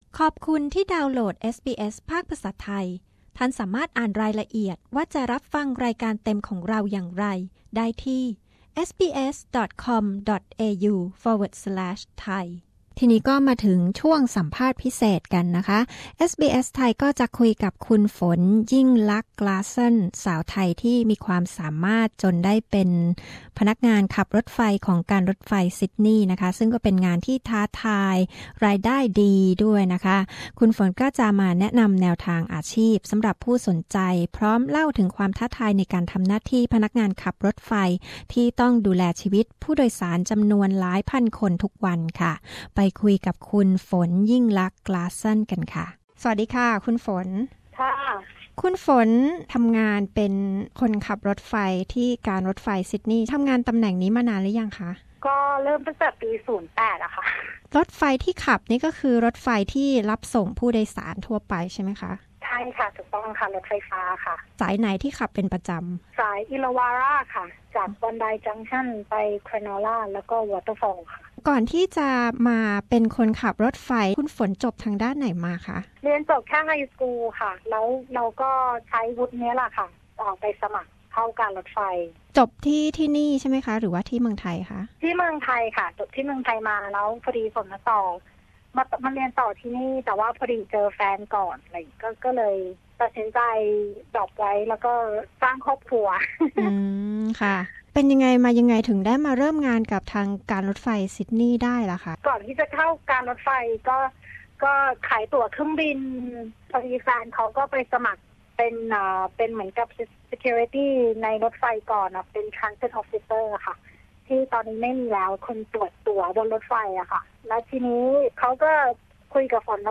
คุยกับพนักงานขับรถไฟสาวไทย